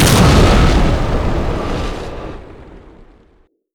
split_earth_tormented.wav